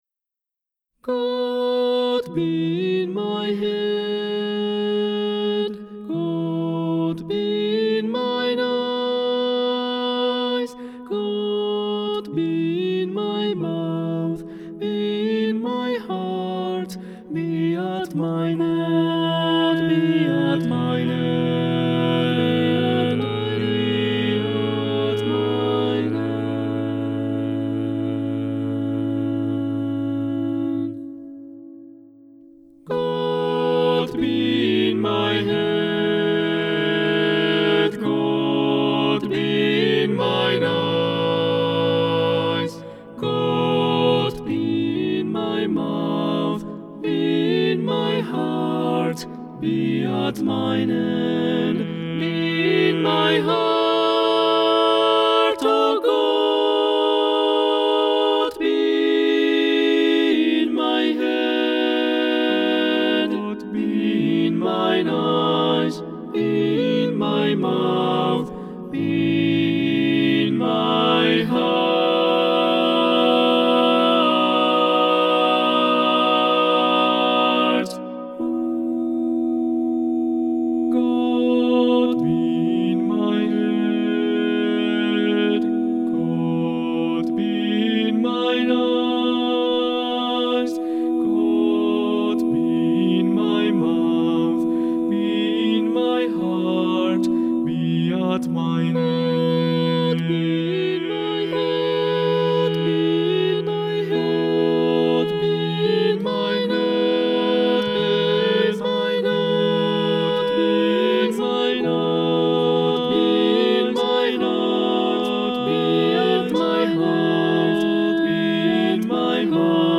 for SATB a cappella